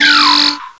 pokeemerald / sound / direct_sound_samples / cries / tympole.aif
-Replaced the Gen. 1 to 3 cries with BW2 rips.
tympole.aif